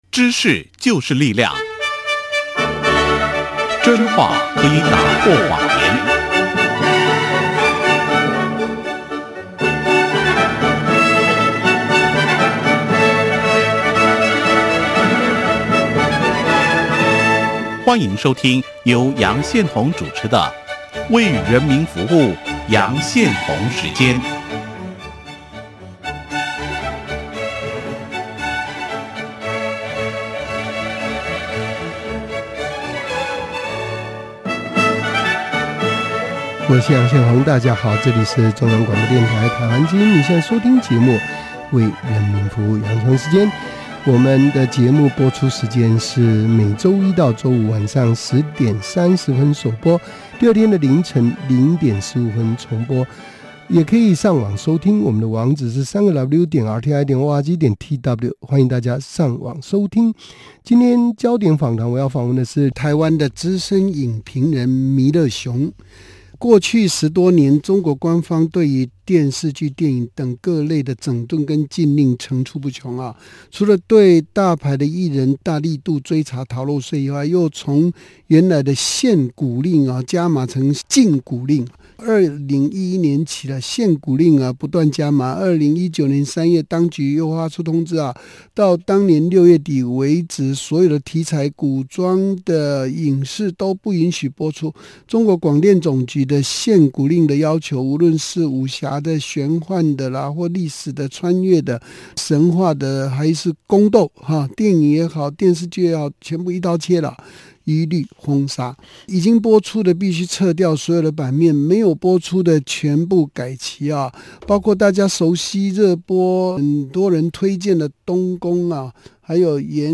廣播影評